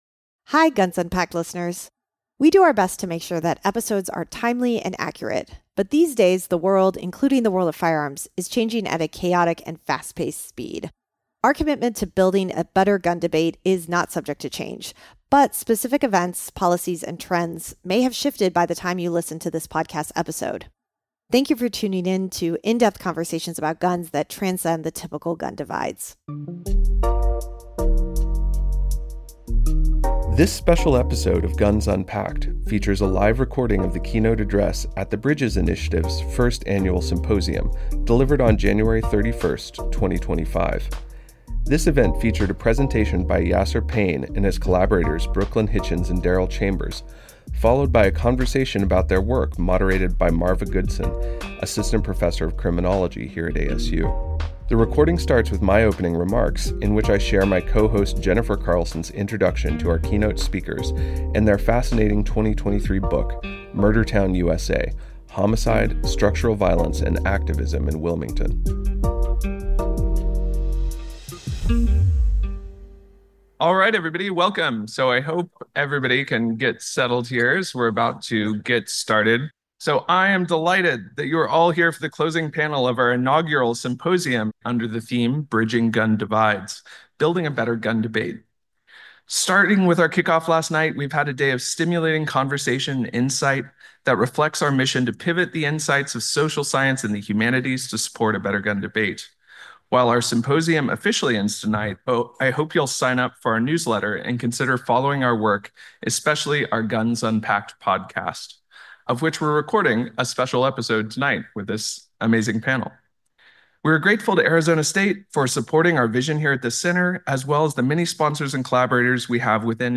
In this special episode of Guns Unpacked, we feature a live recording of the closing panel address at the BRIDGS Initiative’s first ever Guns in Society symposium hosted from January 30-31st, 2025.